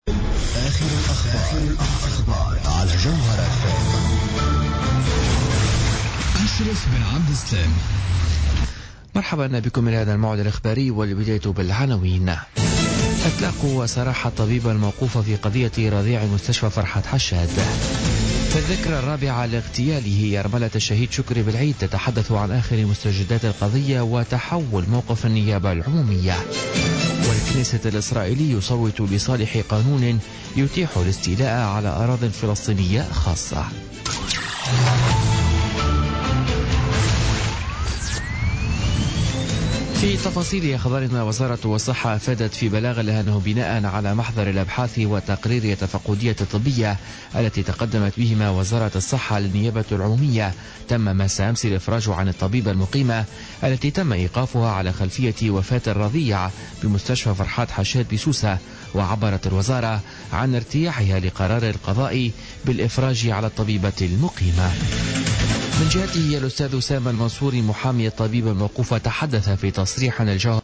نشرة أخبار منتصف الليل ليوم الثلاثاء 7 فيفري 2017